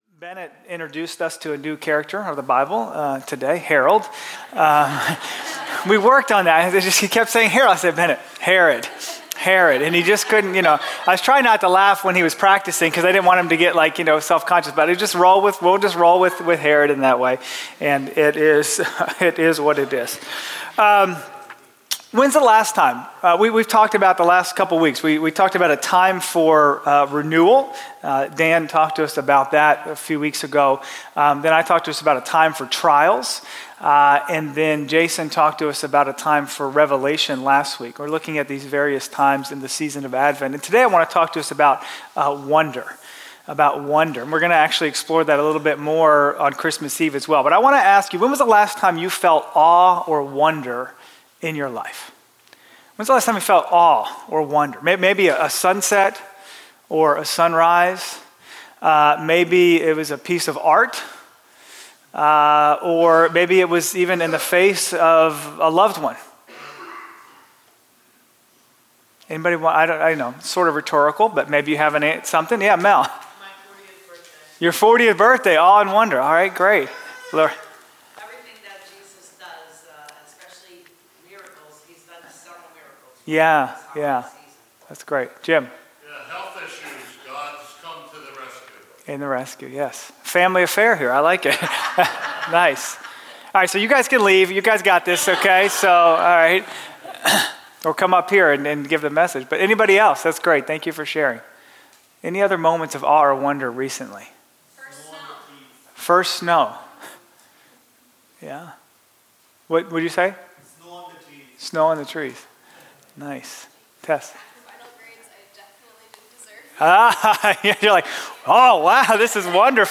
Latest Message